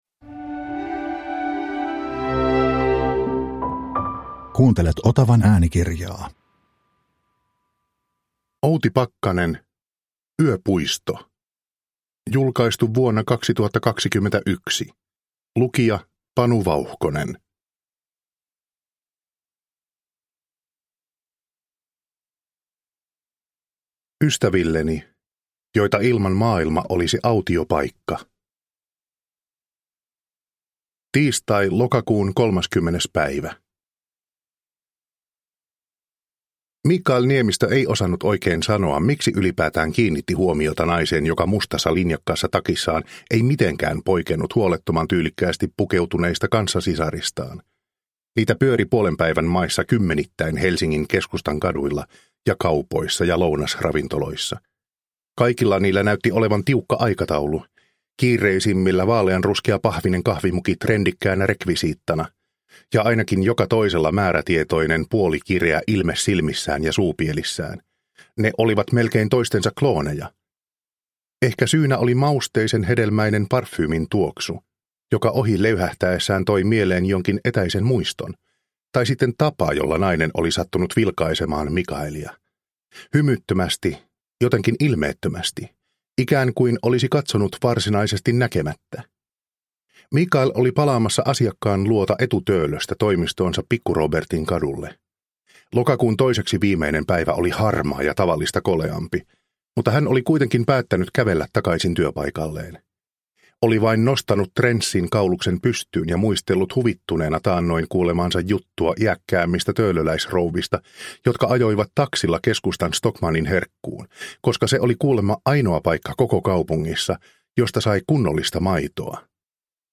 Yöpuisto – Ljudbok